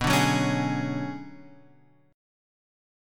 B Minor 6th Add 9th